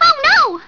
Mario Kart DS Sounds